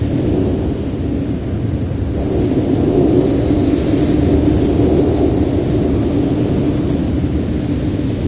skylift_amb.ogg